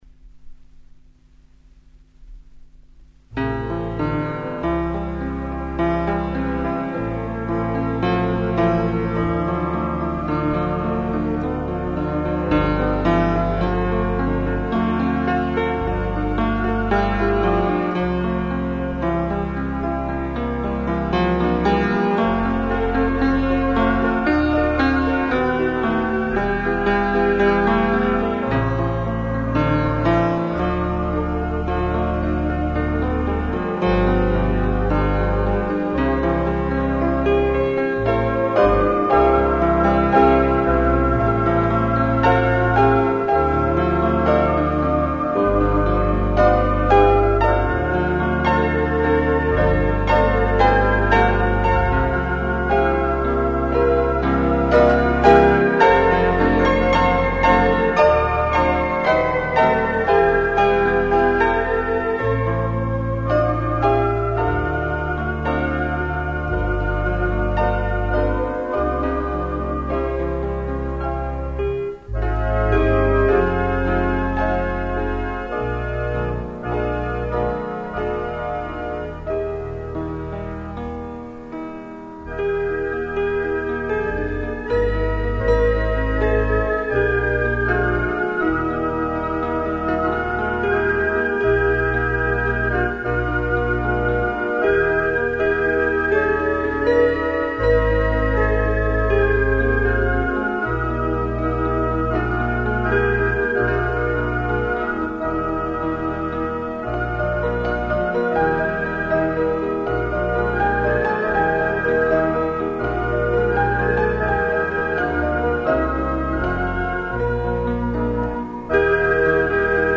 Also many years ago, two LP albums were made of the piano and organ music that featured as part of the worship services at Lansdowne.
Each medley consists of songs and hymns on the theme of the title song.
Organ and Piano music: Holy, holy medley
Holy-Holy-medley-2024-from-vinyl.mp3